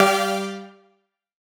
Index of /musicradar/future-rave-samples/Poly Chord Hits/Straight
FR_SOBX[hit]-G.wav